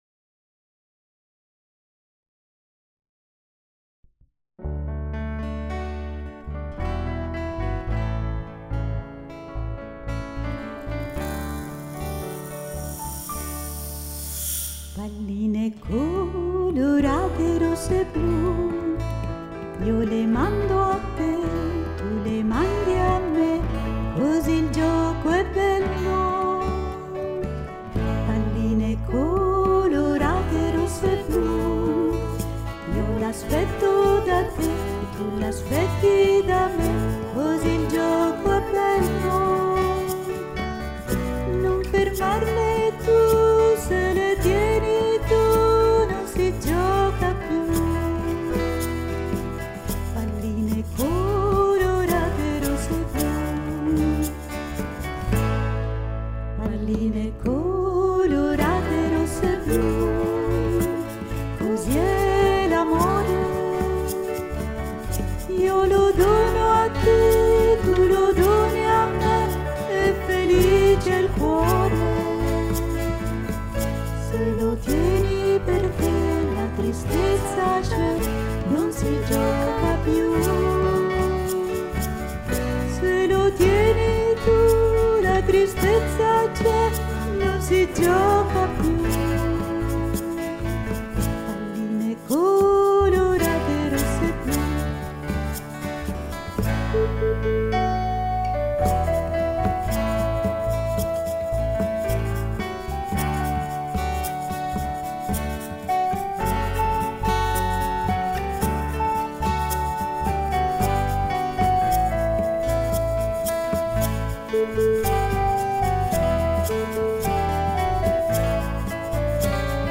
Palline colorate canto mp3